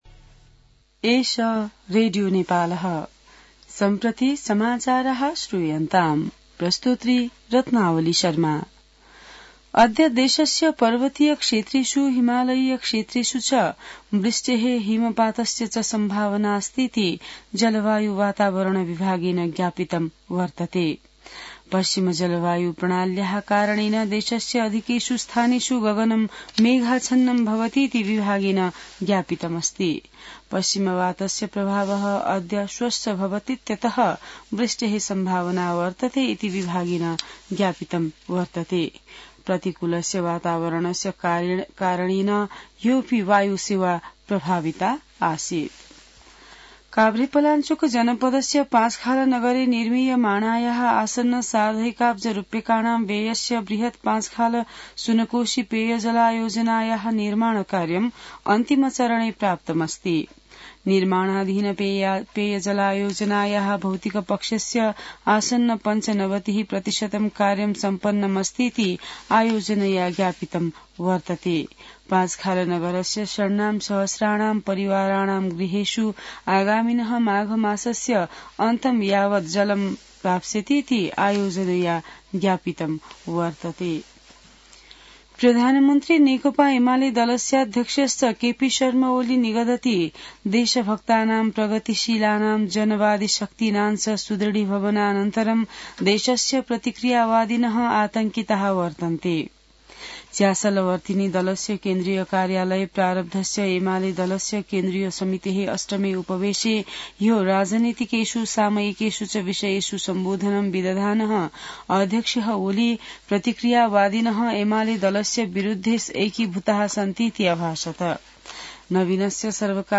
संस्कृत समाचार : २३ पुष , २०८१